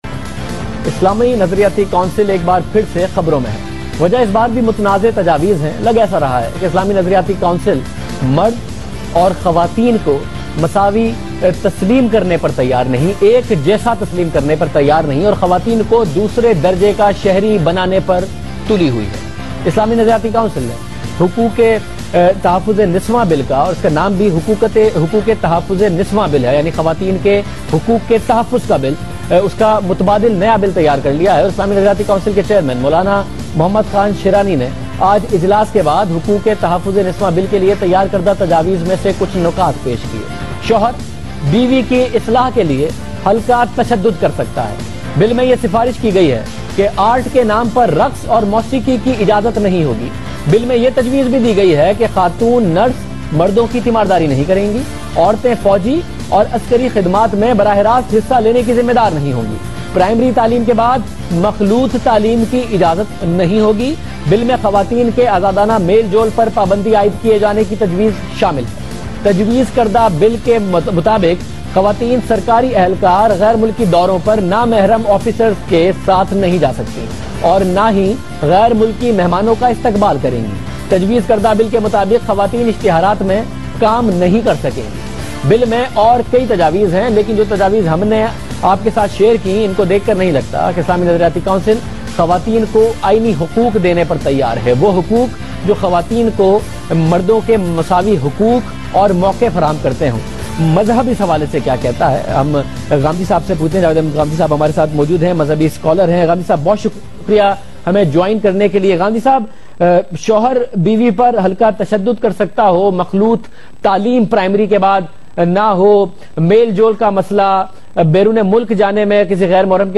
Category: TV Programs / Geo Tv / Questions_Answers /